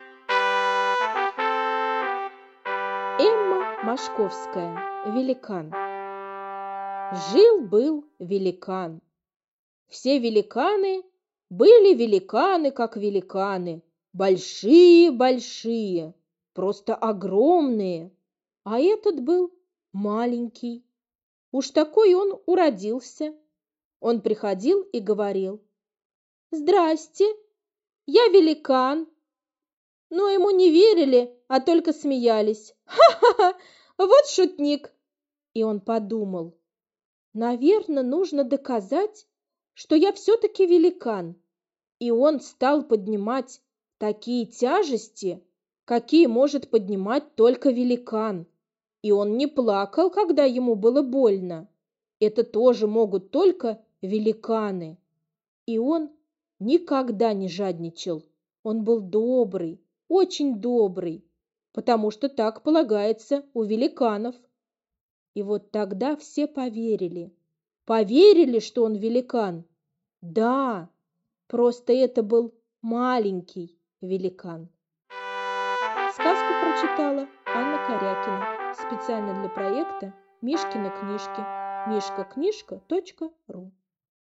Великан - аудиосказка Мошковской Э.Э. Сказка про Великана, который был очень необычным Великаном: он был маленьким Великаном.